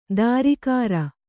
♪ dārikāra